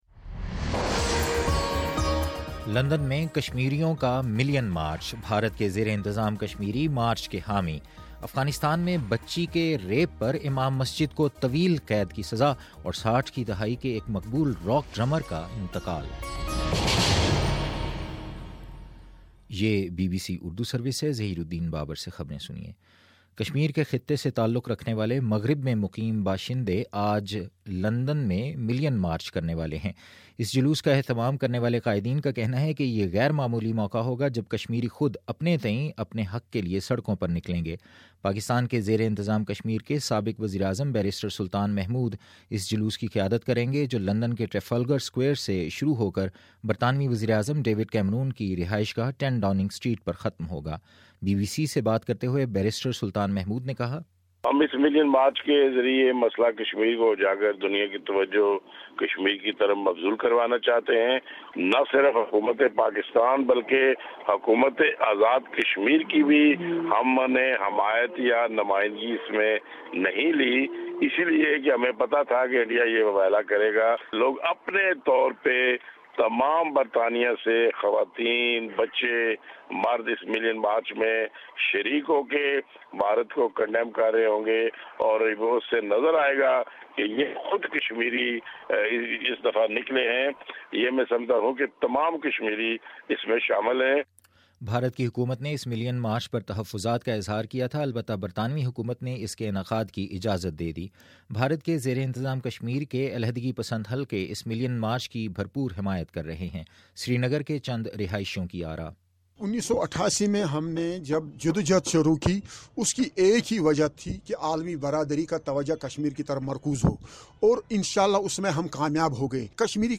اکتوبر26 : صبح نو بجے کا نیوز بُلیٹن
دس منٹ کا نیوز بُلیٹن روزانہ پاکستانی وقت کے مطابق صبح 9 بجے، شام 6بجے اور پھر 7 بجے۔